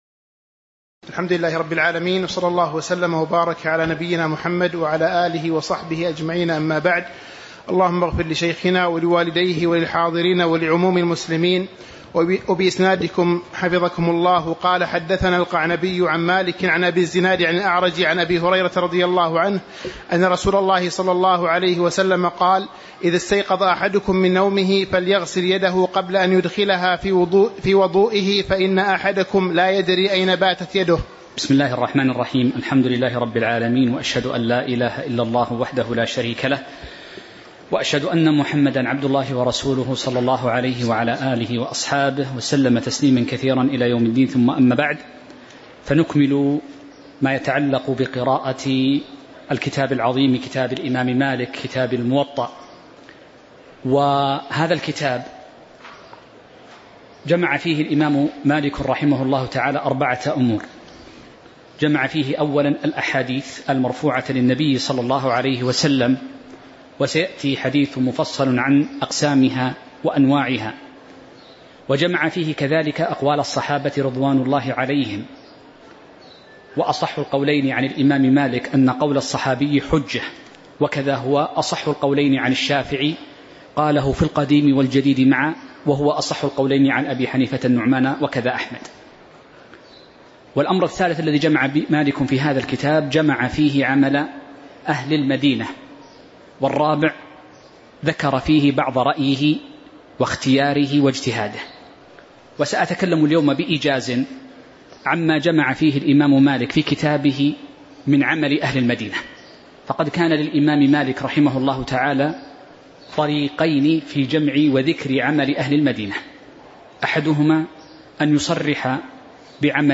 تاريخ النشر ٣ جمادى الآخرة ١٤٤٥ هـ المكان: المسجد النبوي الشيخ